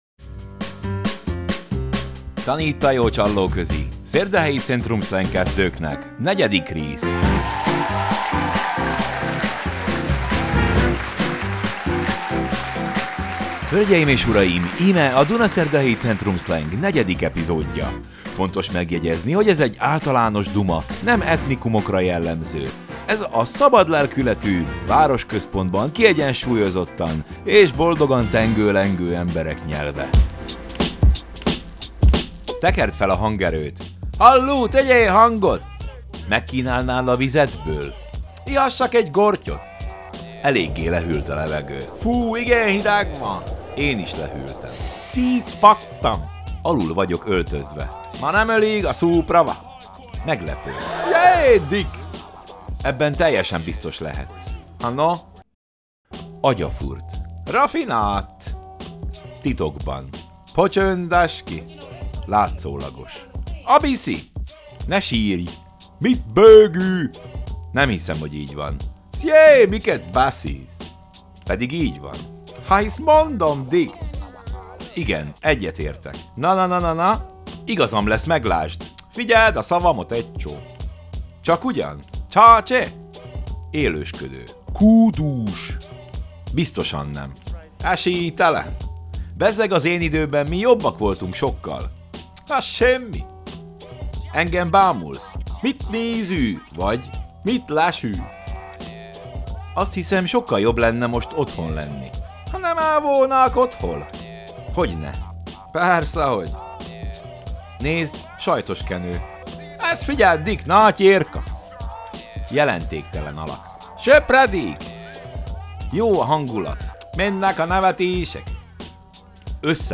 Zene:
Fun Retro Motown - Upbeat